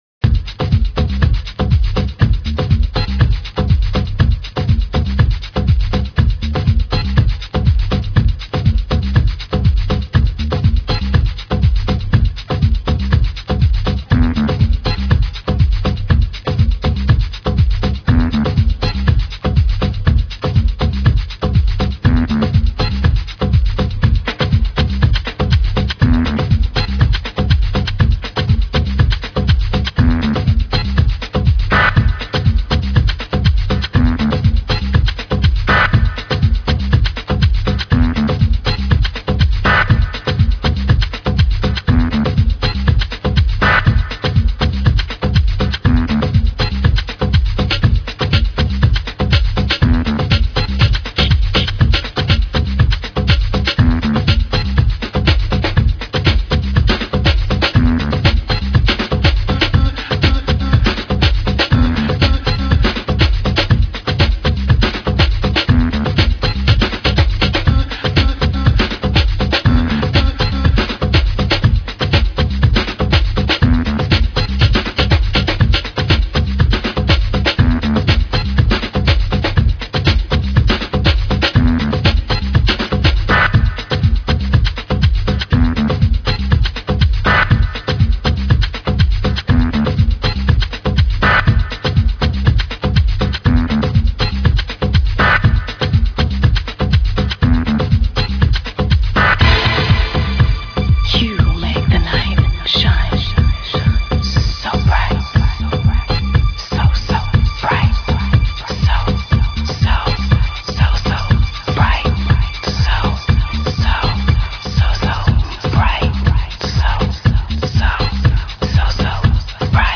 soulful house piece